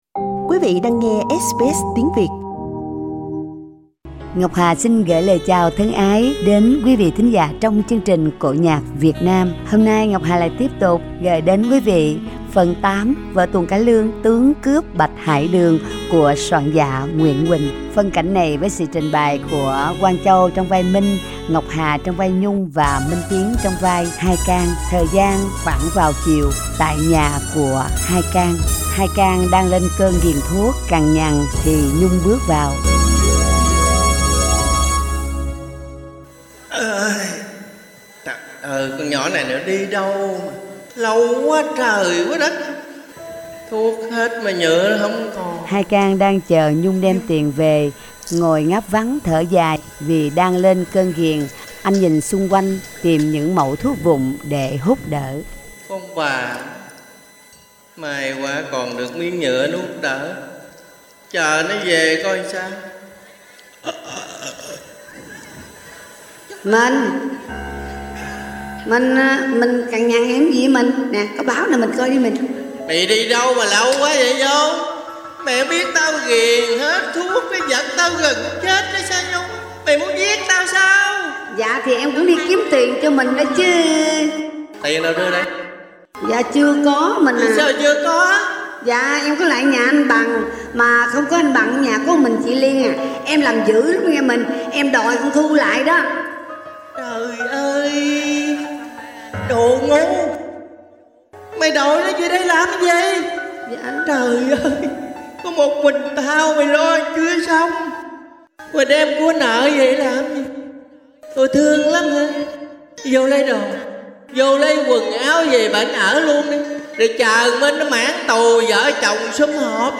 Câu chuyện xảy ra và kết cuộc như thế nào xin mời quý vị cùng theo dõi Phần 8 vở Cải Lương 'Tướng cướp Bạch Hải Đường' của soạn giả Nguyễn Huỳnh, do anh chị em nghệ sĩ Úc Châu trình diễn tại Sydney và sẽ phát phần cuối vào thứ bẩy tuần sau.